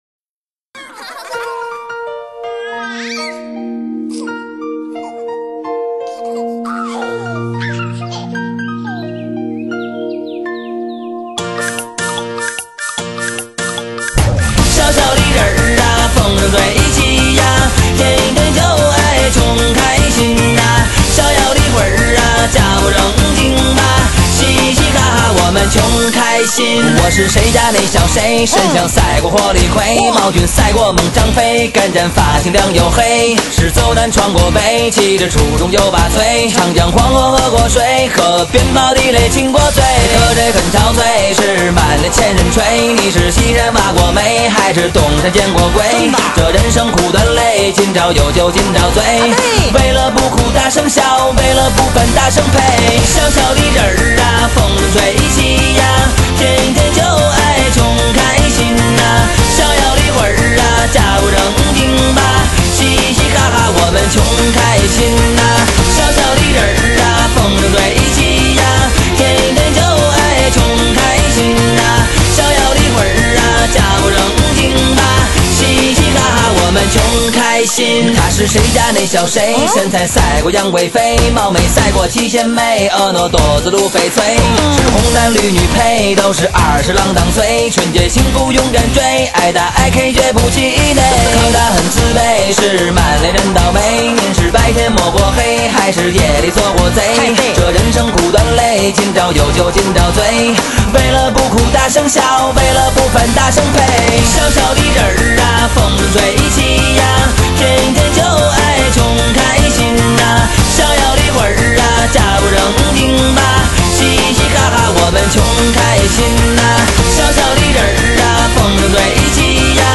很有北京味儿的一首歌